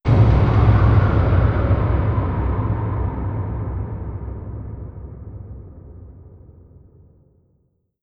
Jumpscare_03.wav